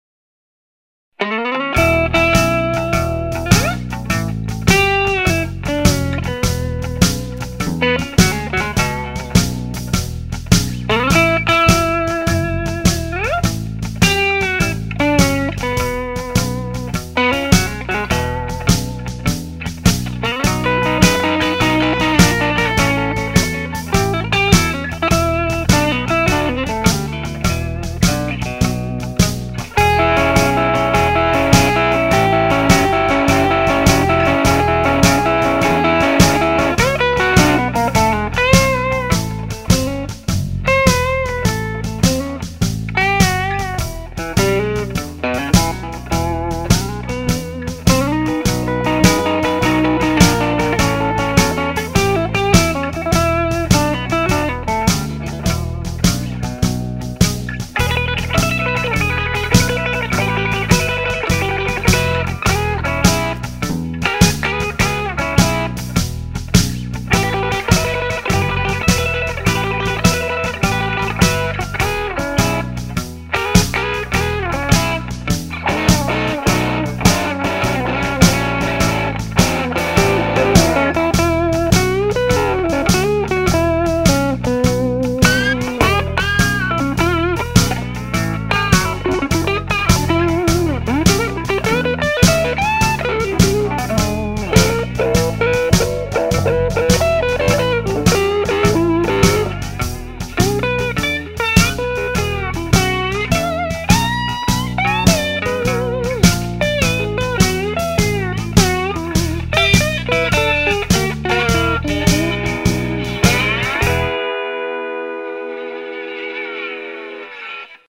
(1.8MB--2min--128K stereo)